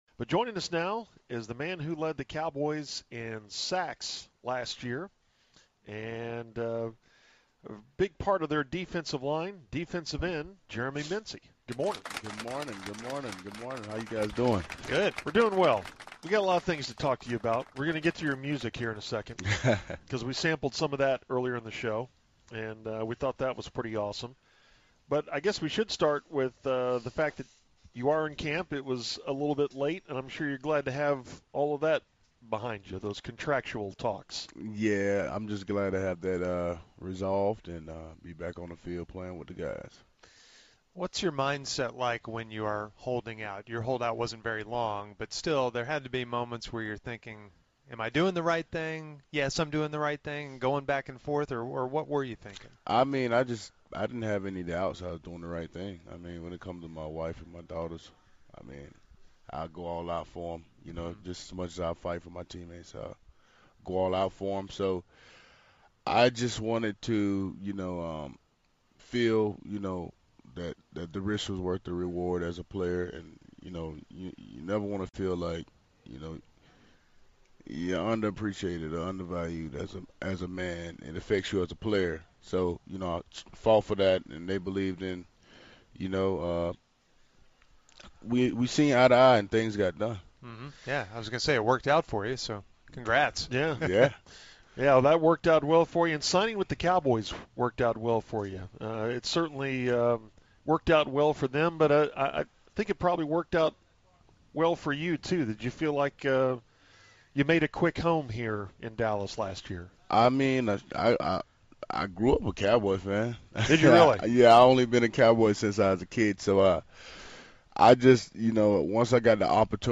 Musers Interview Jeremy Mincey – 8.5.15
The Musers talk to football player man Jeremy Mincey about his rap career. Probably the most entertaining Cowboy interview you will hear this training camp.
musers-interview-jeremy-mincey-8.5.15.mp3